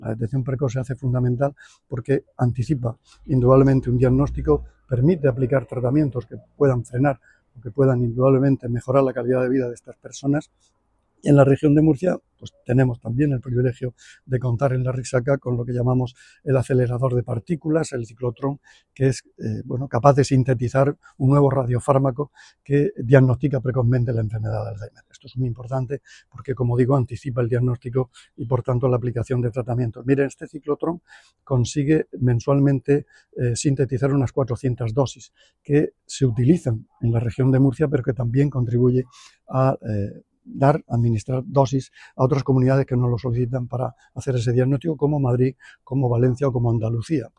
Sonido/ Declaraciones del consejero de Salud, Juan José Pedreño, sobre la producción de radiofármacos para la detección precoz del alzhéimer.